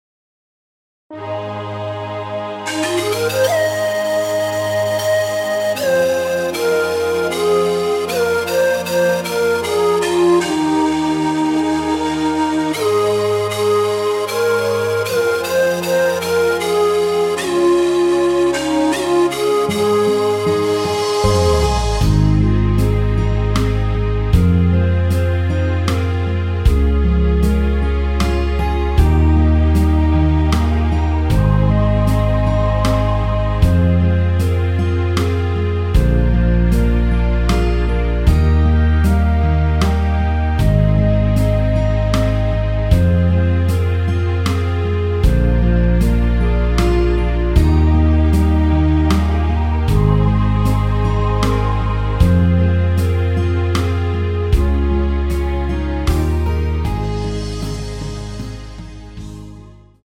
원곡 반주는 박자 맞추기 어려워서 새롭게 편곡 하였습니다.
원키 멜로디 포함된 MR 입니다.(미리듣기 참조)
Ab
앞부분30초, 뒷부분30초씩 편집해서 올려 드리고 있습니다.
중간에 음이 끈어지고 다시 나오는 이유는